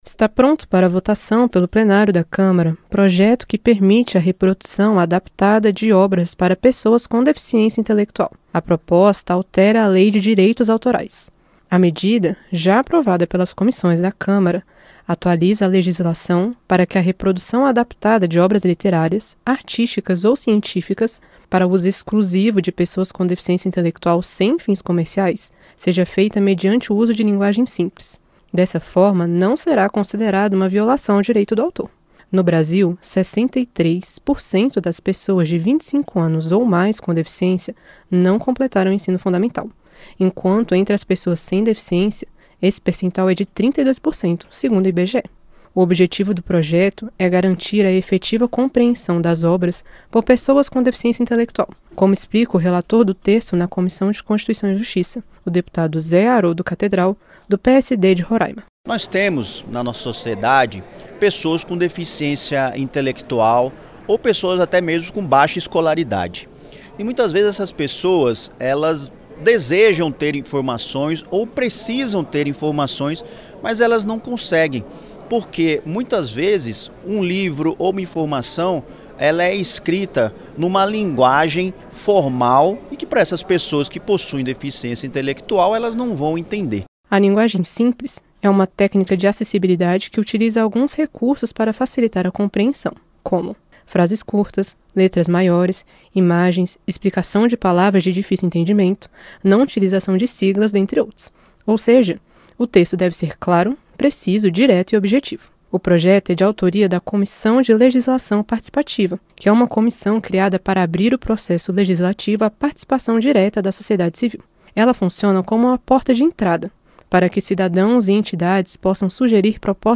PROJETO QUE PERMITE REPRODUÇÃO DE OBRAS LITERÁRIAS EM LINGUAGEM SIMPLES ENTRA EM FASE FINAL DE VOTAÇÃO NA CÂMARA. A REPORTAGEM